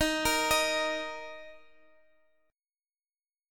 D#5 chord